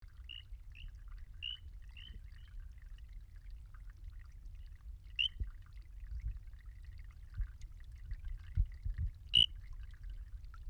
Especie: Centrolene buckleyi
Localidad: Ecuador: Loja: Abra de Zamora, 13 km E Loja
Nombre común: Ranita de cristal
7909Centrolene buckleyi.mp3